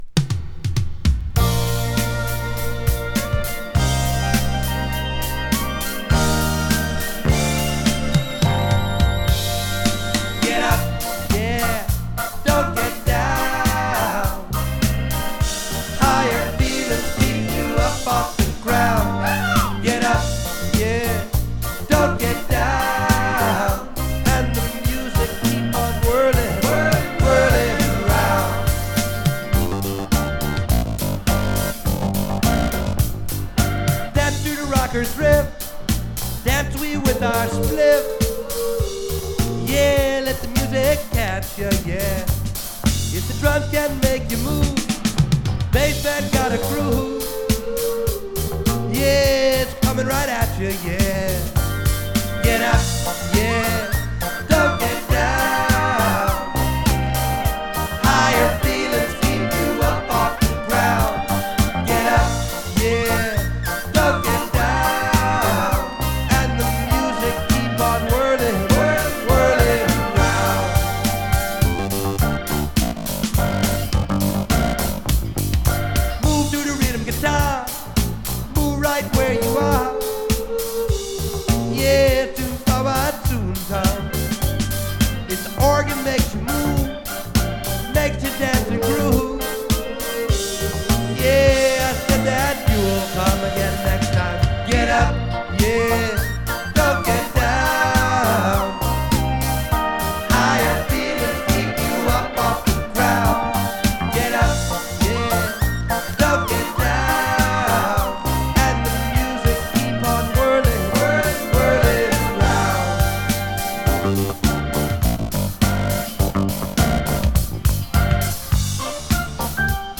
Indie Reggae from Texas！ オースティン出身のレゲエ・グループ。
【REGGAE】